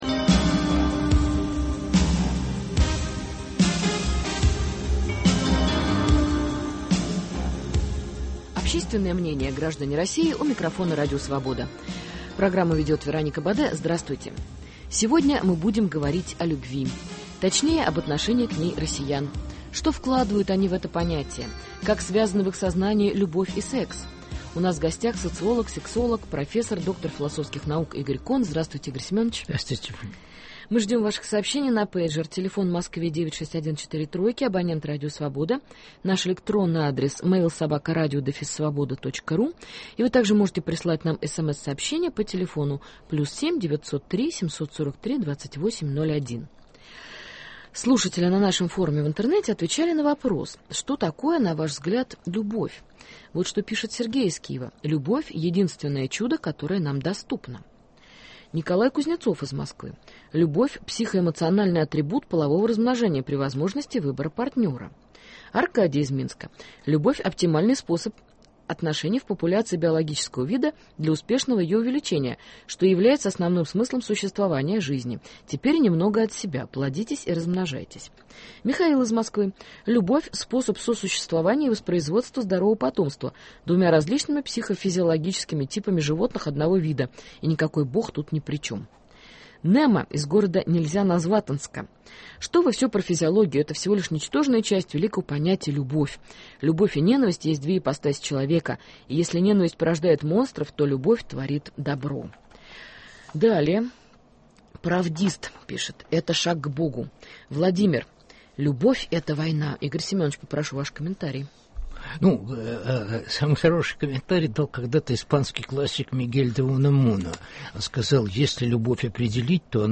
Как связаны в их сознании любовь и секс? В гостях у Радио Свобода – социолог, сексолог, доктор философских наук Игорь Кон.